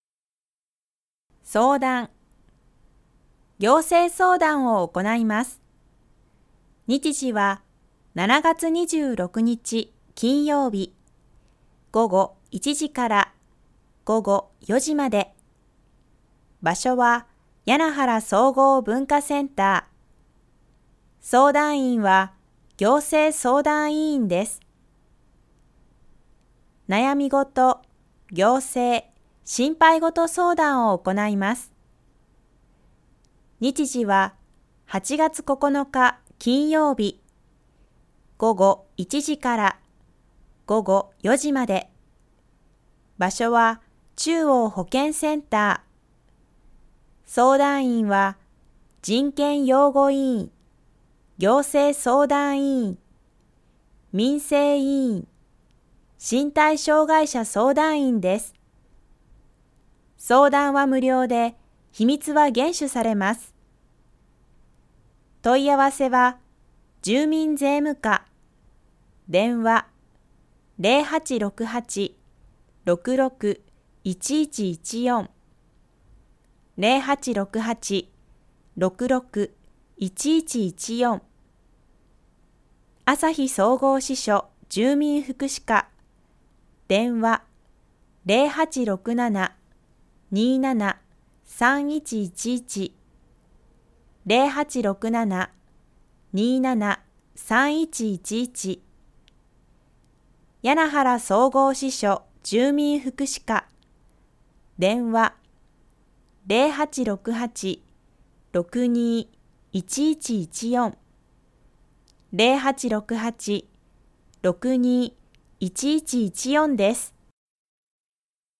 広報誌の一部を読み上げています。